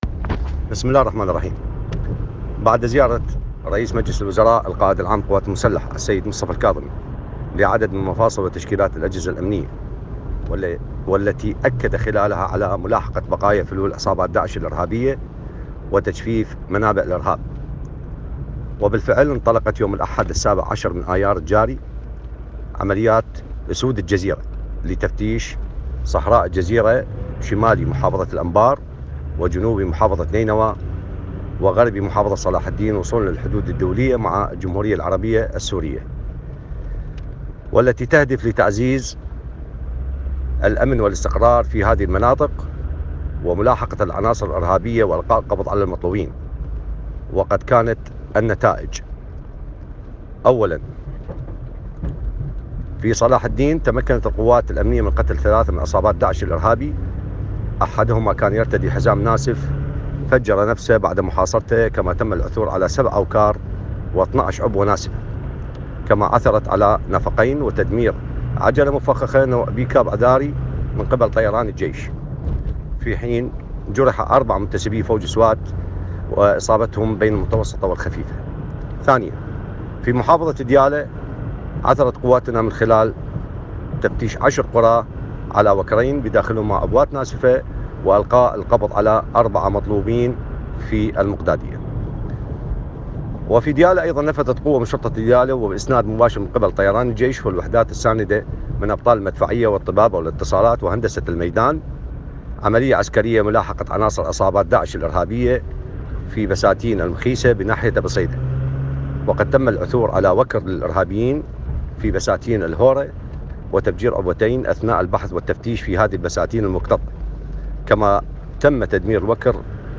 وقال رسول، في تسجيل صوتي حصل موقع كتابات في الميزان على نسخة منه ، إنه “بعد زيارة رئيس الوزراء القائد العام للقوات المسلحة مصطفى الكاظمي لعدد من مفاصل وتشكيلات الأجهزة الأمنية،  والتي أكد خلالها على ملاحقة فلول عصابات داعش الارهابية، وتجفيف منابع الإرهاب، انطلقت عملية أسود الجزيرة يوم الأحد 17 أيار الجاري لتفتيش صحراء جزيرة شمال محافظة الانبار، وجنوب محافظة نينوى، وغرب محافظة صلاح الدين”، مبيناً ان “هذه العملية تهدف إلى تعزيز الأمن والاستقرار في هذه المناطق، وملاحقة العناصر الإرهابية وإلقاء القبض على المطلوبين”.